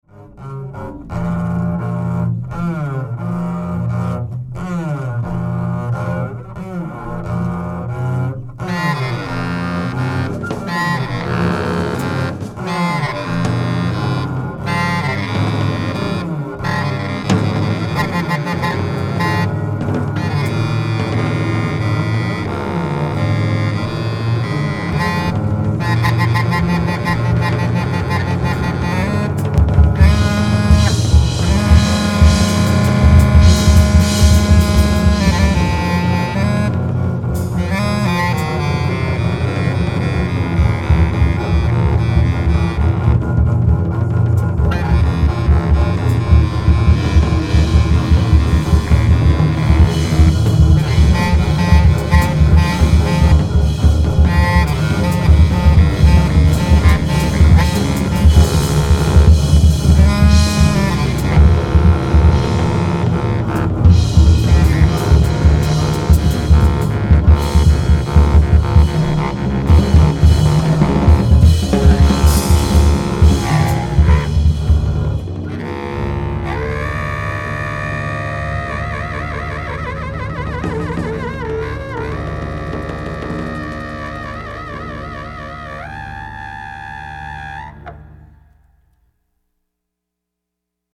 acoustic bass
drums